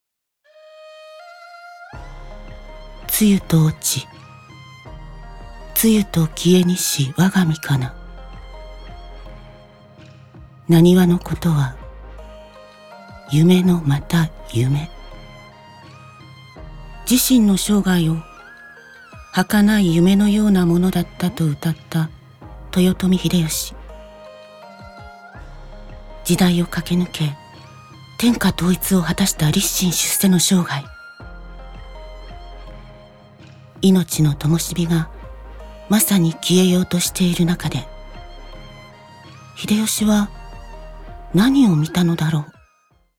ボイスサンプル
2.新録 歴史NA(人物②)